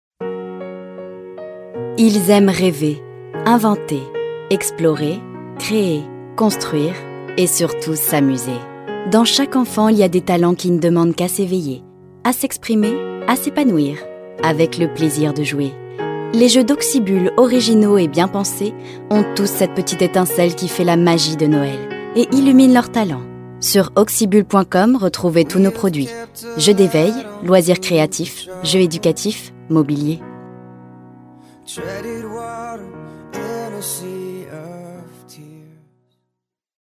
Bandes-son
Publicité Ducan